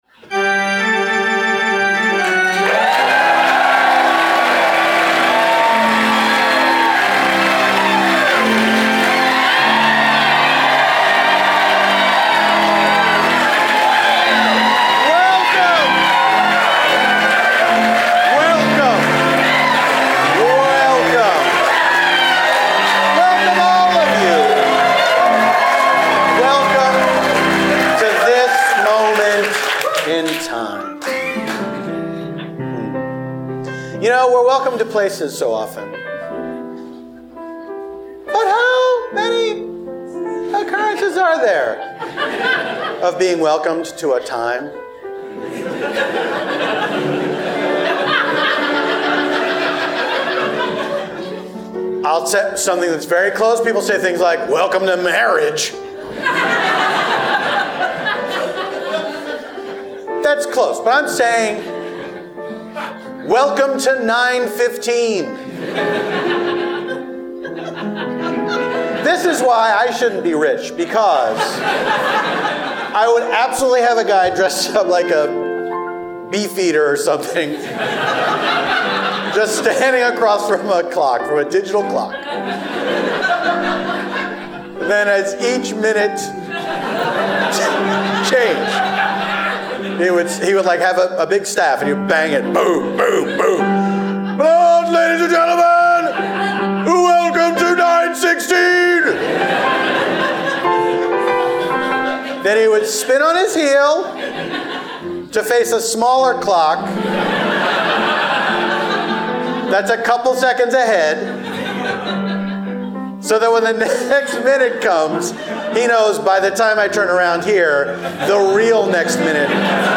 Paul F. Tompkins welcomes the beautiful crowd of Detroit to Spontaneanation!
Then, they are joined by improvisers Eugene Cordero, Tawny Newsome, and Little Janet Varney, to improvise a story set In Line at a Delayed Lauryn Hill Concert.
scores it all on piano